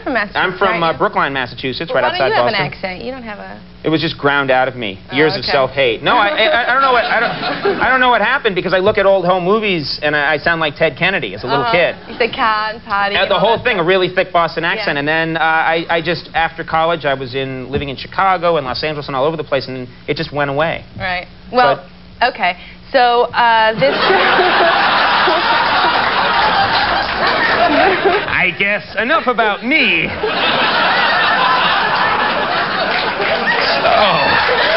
Tags: Television Conan O' Brien Show Comedy Talk Show Guests